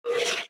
Minecraft Version Minecraft Version snapshot Latest Release | Latest Snapshot snapshot / assets / minecraft / sounds / mob / stray / idle1.ogg Compare With Compare With Latest Release | Latest Snapshot